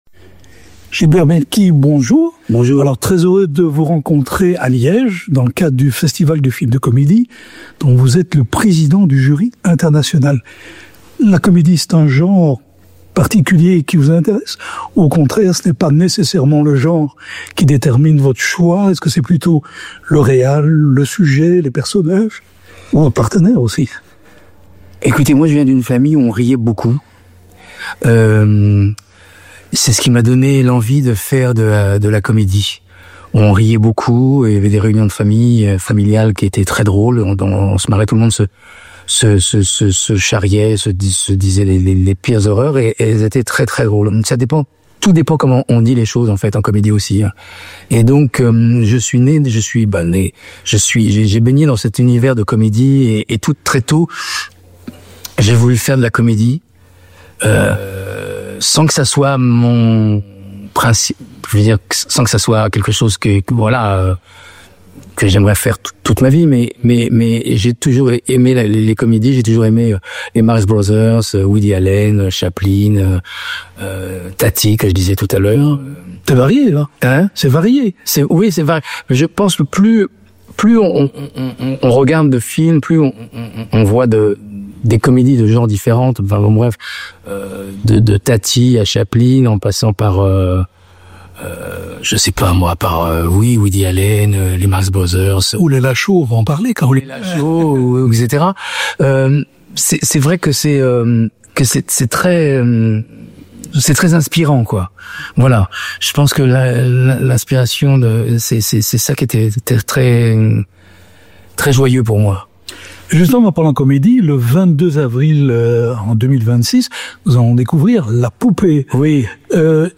Il est le Président du jury international de la 10ᵉ édition du FIFCL. Rencontre.